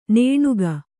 ♪ nēṇuga